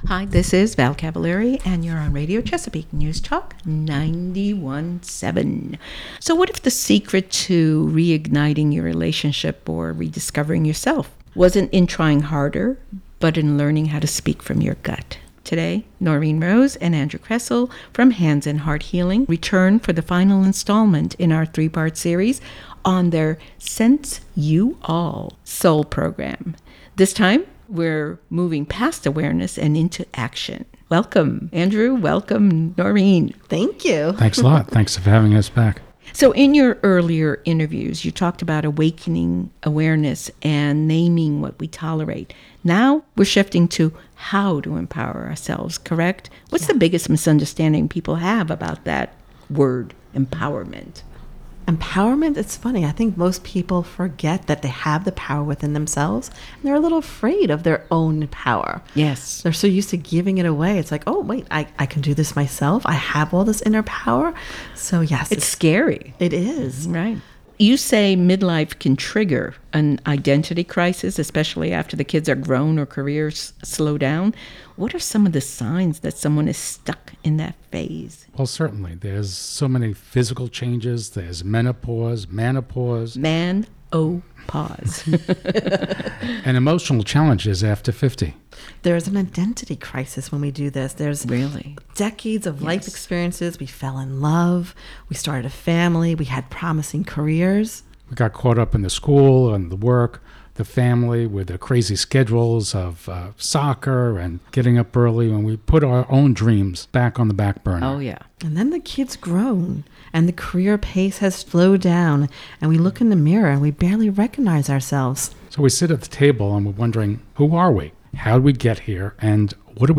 WHCP interview